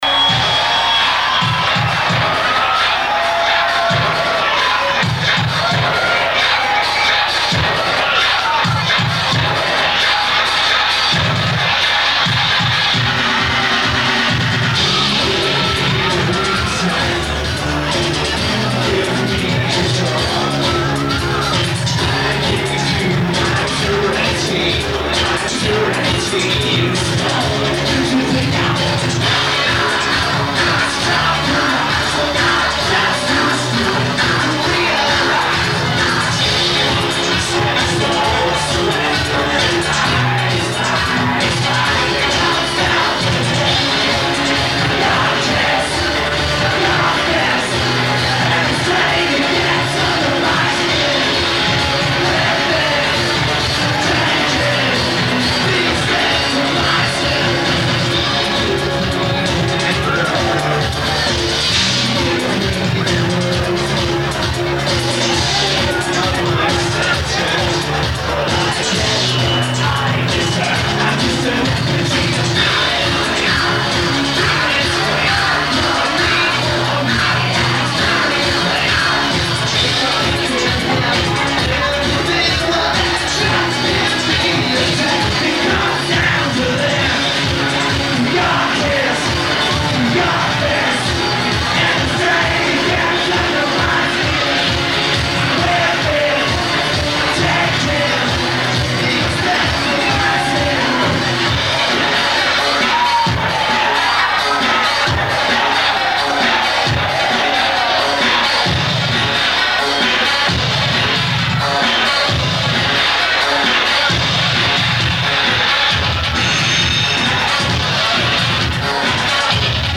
Club Nu
Lineage: Audio - AUD, Unknown Video Source